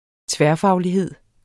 Udtale [ ˈtvεɐ̯- ]